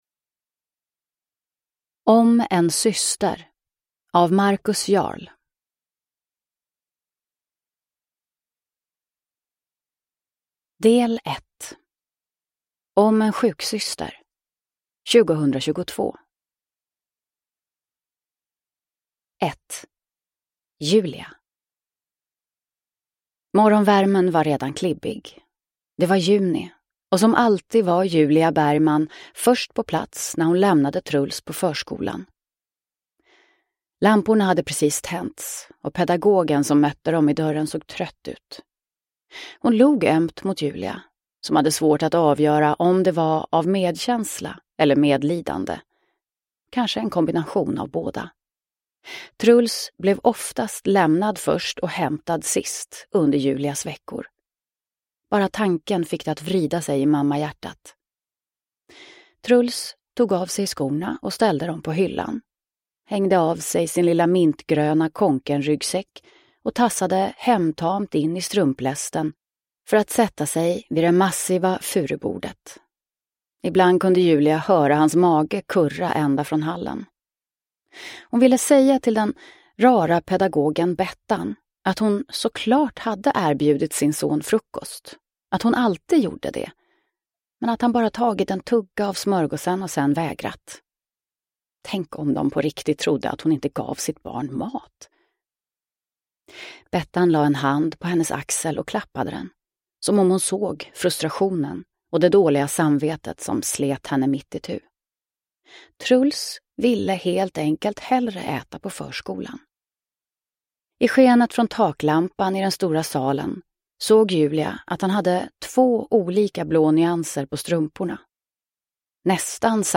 Om en syster – Ljudbok – Laddas ner